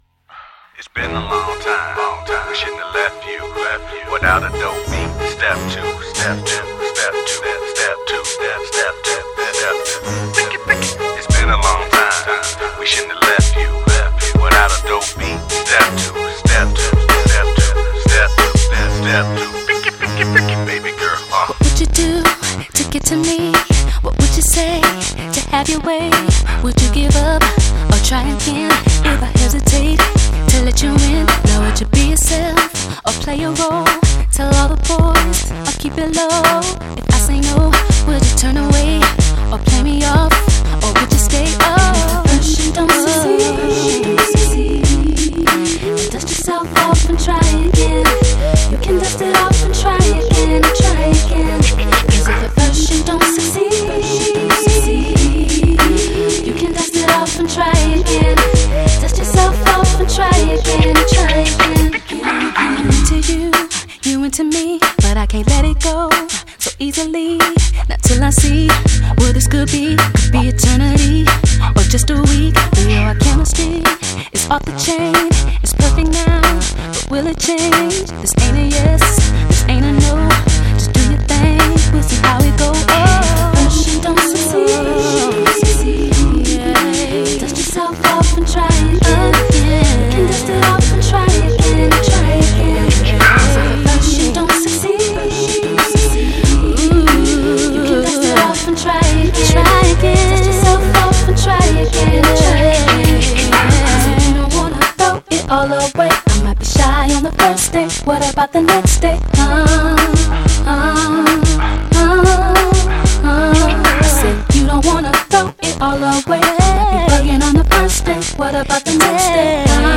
Жанр: R&B